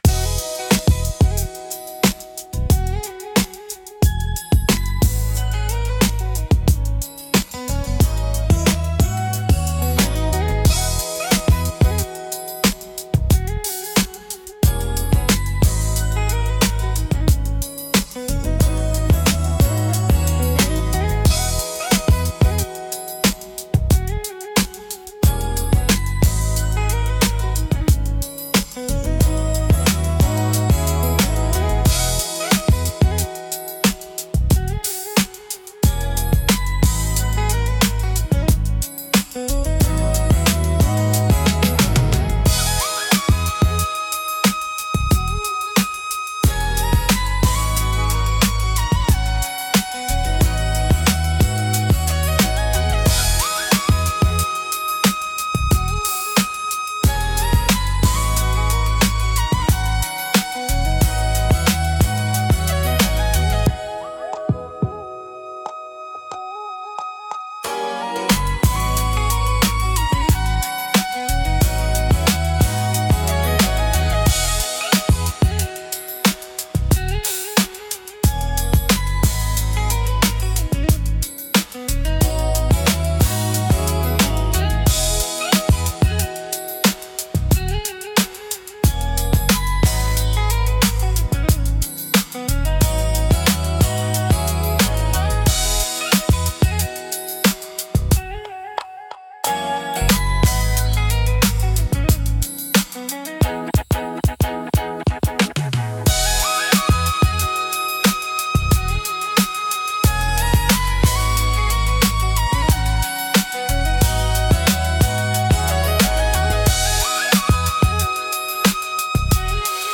リズムの重厚感とグルーヴ感、感情豊かなボーカルが特徴で、深みと熱量のあるサウンドが魅力です。